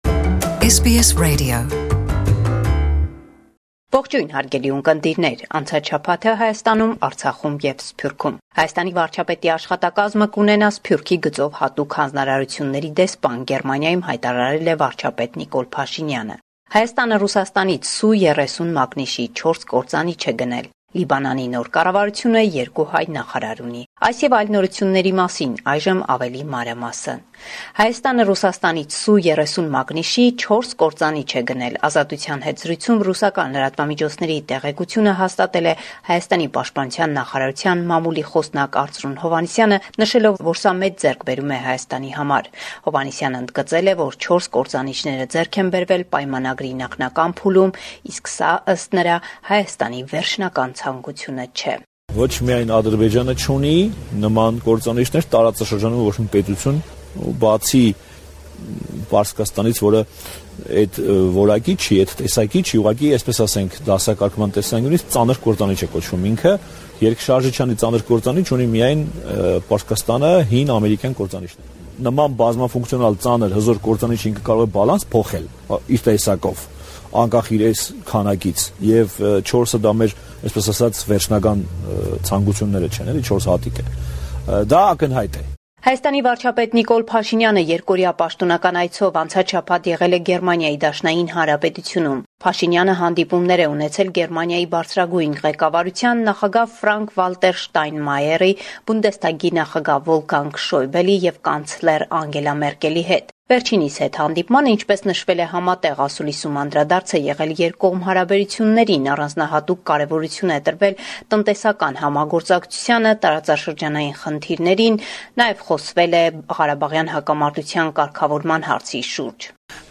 Latest news - 5 February 2019